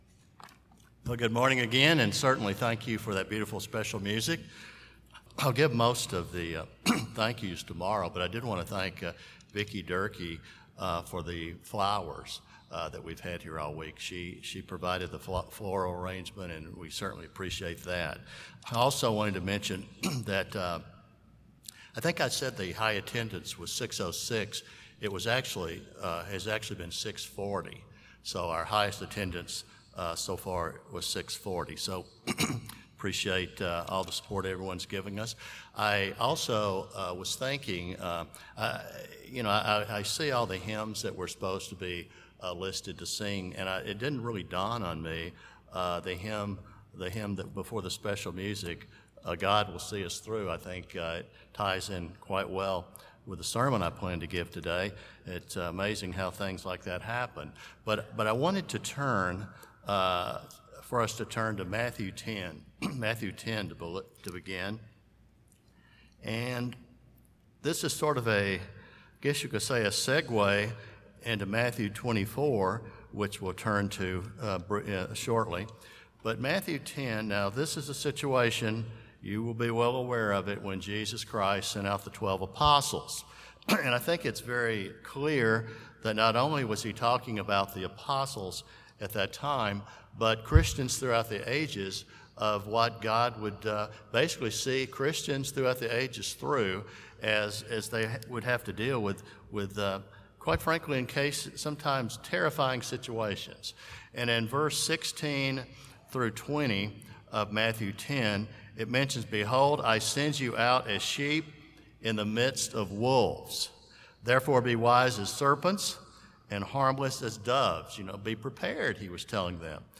This sermon was given at the Gatlinburg, Tennessee 2020 Feast site.